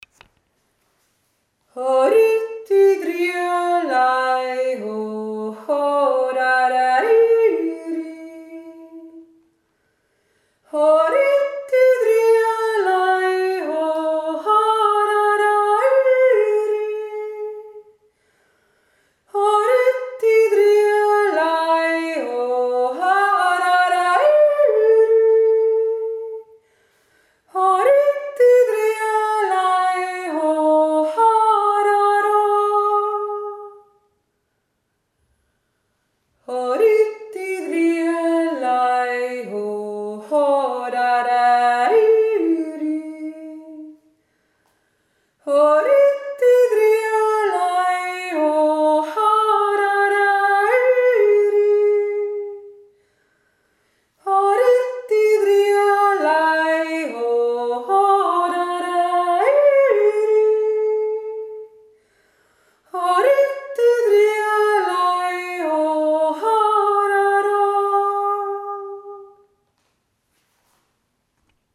Horiti drialei ho - Jodler
1. Stimme und
2. Stimme